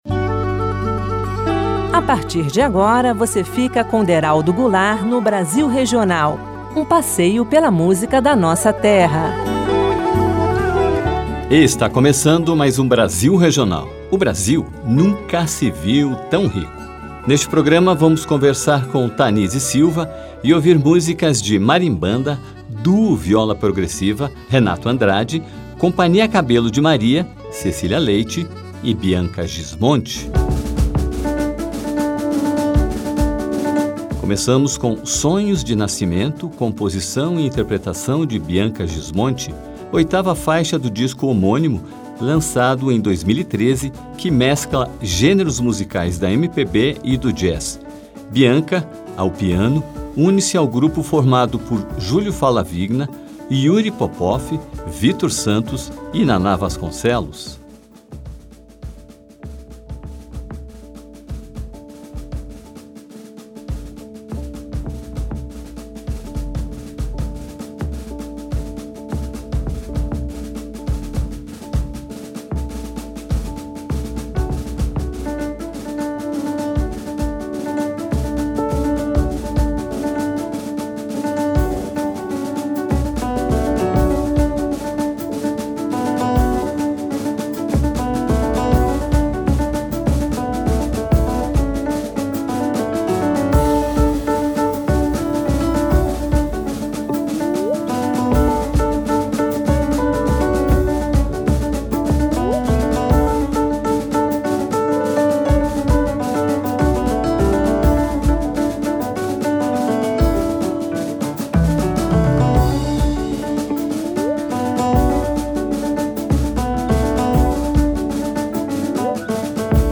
compositora, arranjadora, professora e flautista.